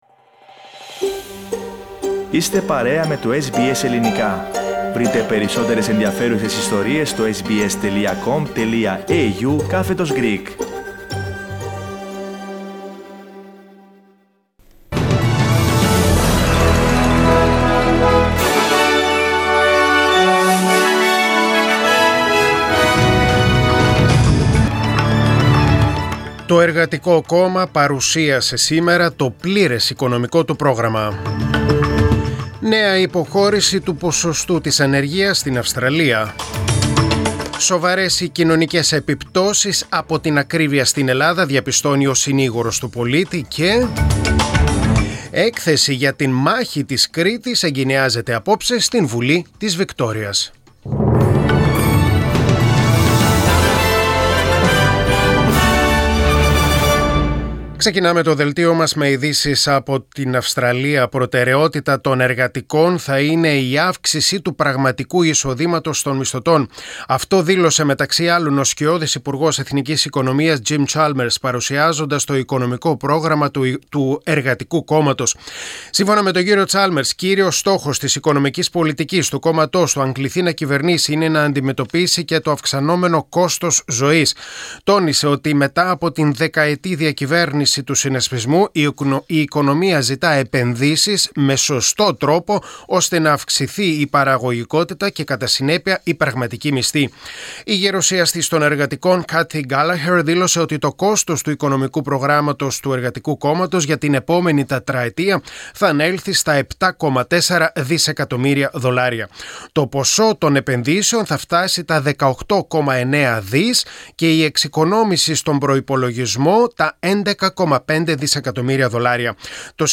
Δελτίο Ειδήσεων: Πέμπτη 19.5.2022